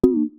Ding-Error-Pitch-Drop-03.wav